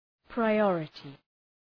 Προφορά
{praı’ɔ:rətı}
priority.mp3